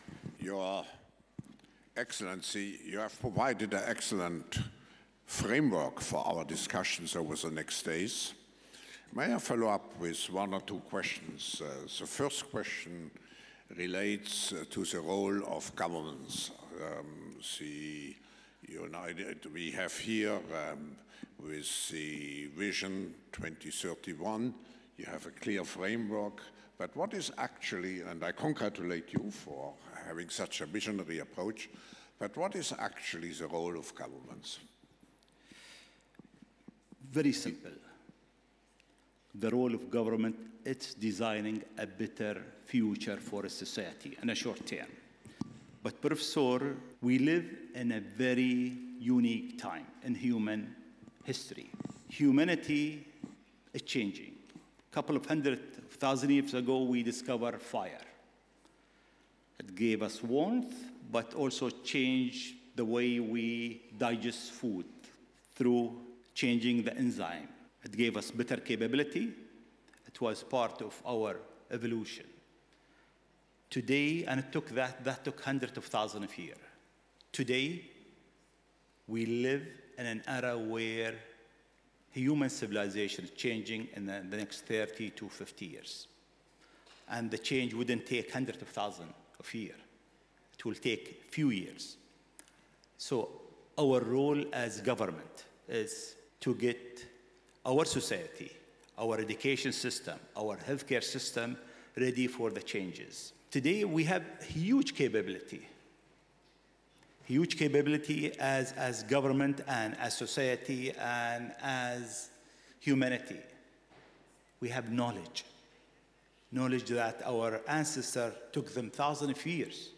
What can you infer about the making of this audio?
at Stanford University.